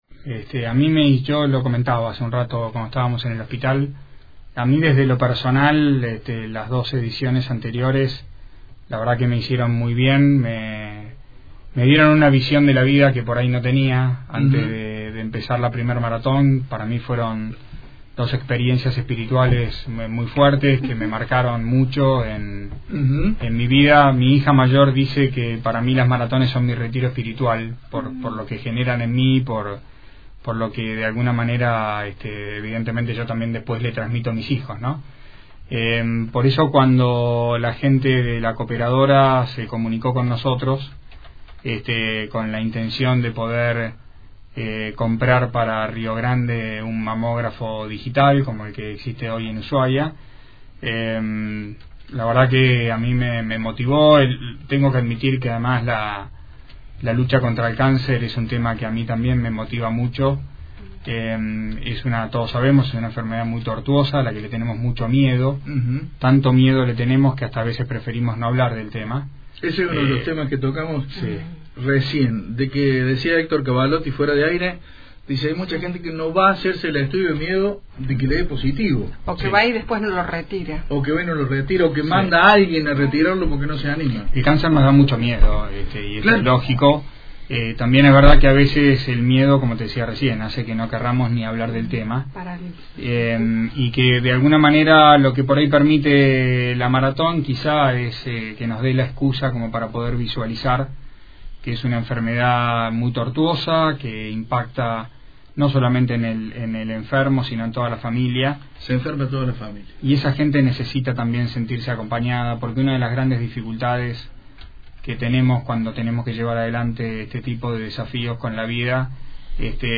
En los estudios de Radio Fueguina, el intendente de Ushuaia, Federico Sciurano habló sobre una nueva maratón solidaria que se realizará en el mes de setiembre